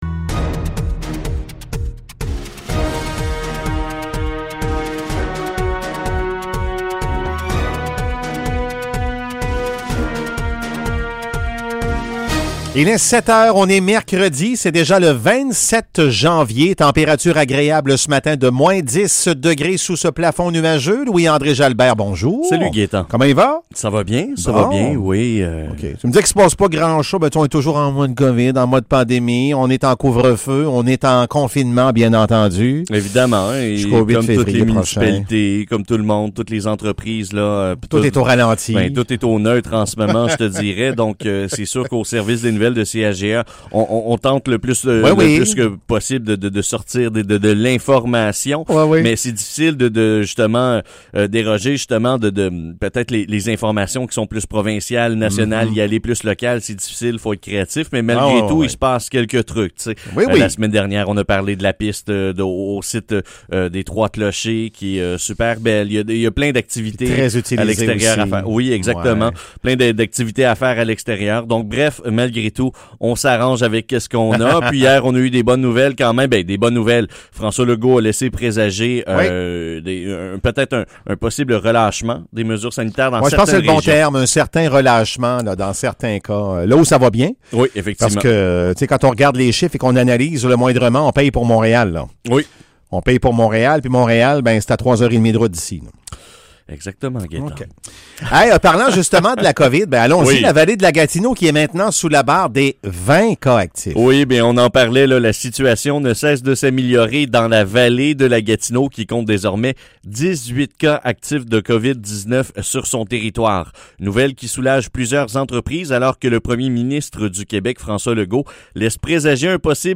Nouvelles locales - 27 janvier 2021 - 7 h